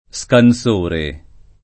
scansore [ S kan S1 re ] s. m.